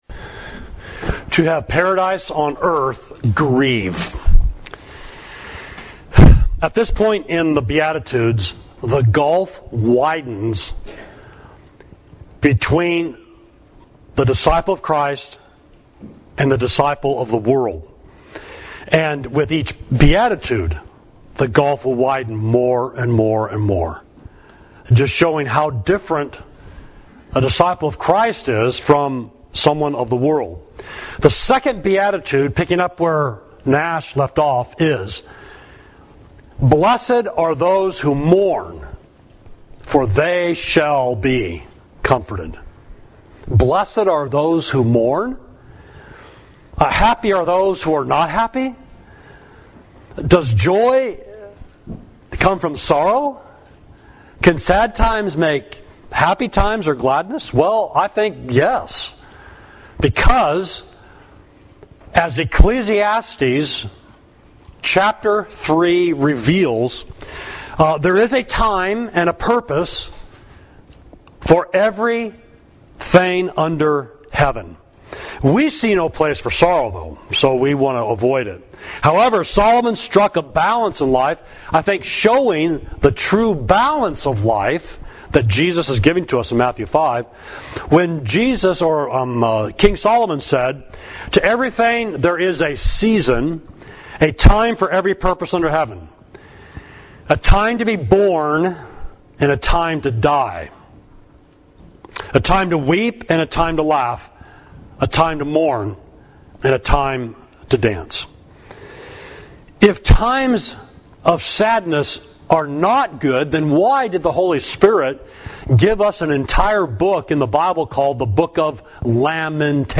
Sermon: To Have Paradise on Earth – Grieve, Matthew 5.4